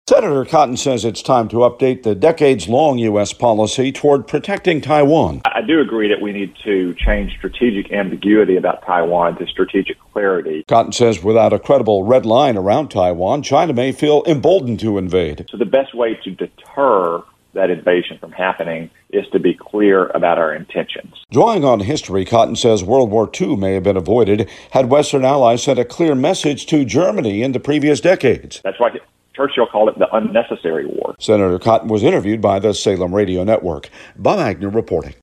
Reporting from Capitol Hill